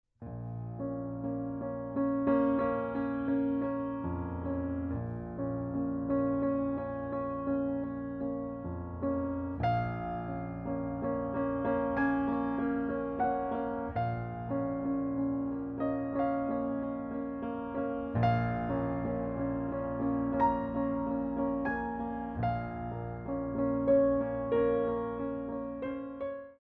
6/8 - 64 +8 with repeat
4 Count introduction included for all selections